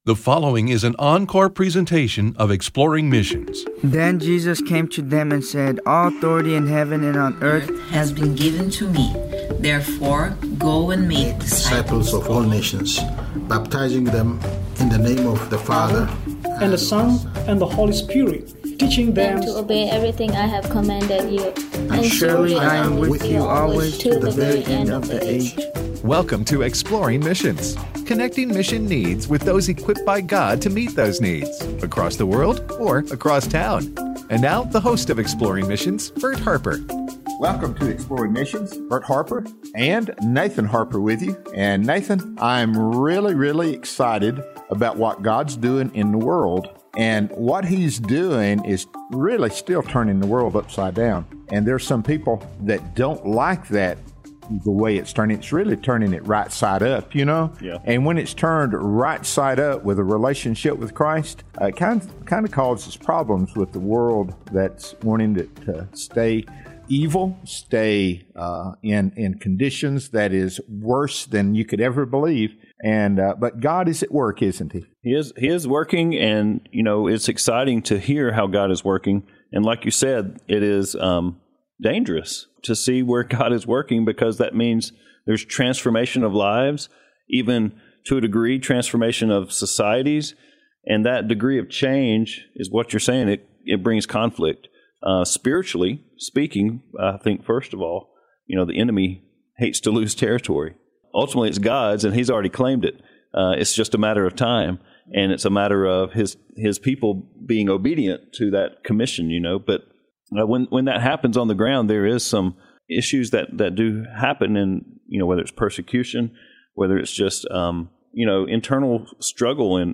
Bibles for the Nations: A Conversation